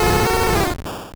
Cri de Goupix dans Pokémon Or et Argent.